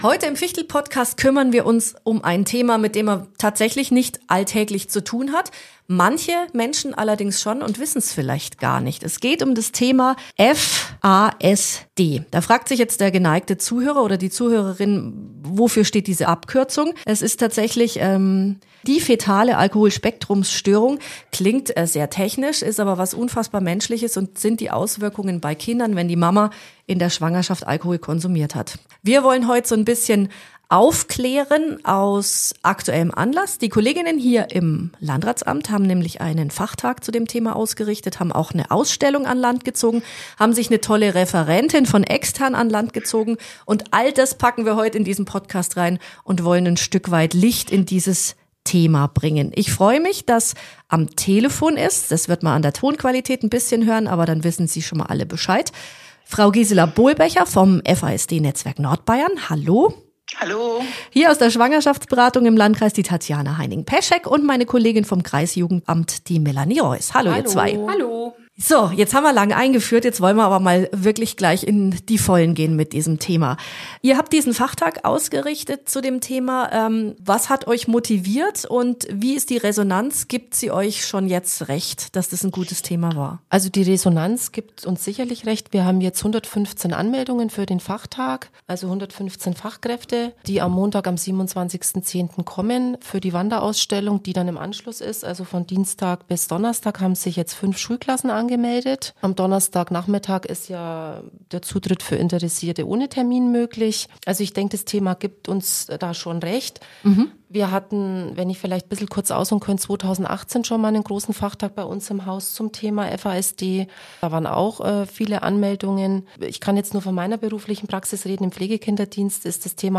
Ein offenes, ehrliches Gespräch über Verantwortung, Aufklärung und die Stärke von Familien, die Kinder mit FASD begleiten.